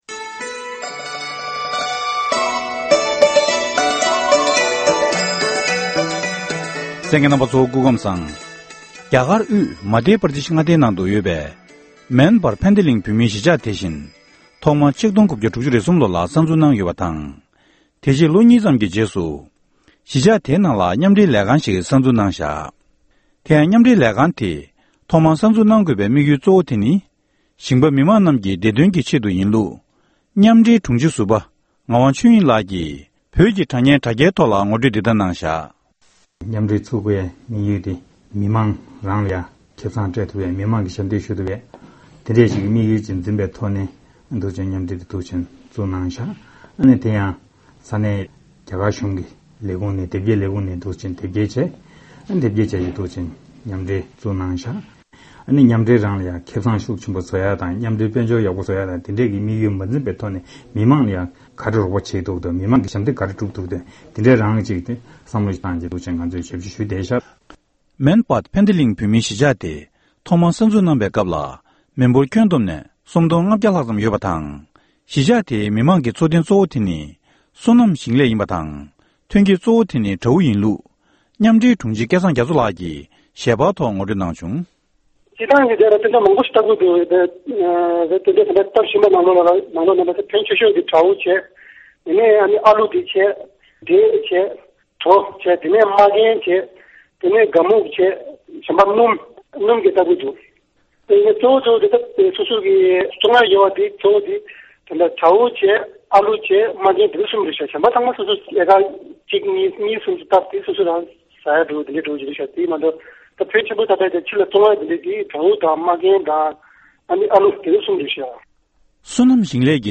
འབྲེལ་ཡོད་མི་སྣར་བཀའ་འདྲི་ཞུས་པར་གསན་རོགས༎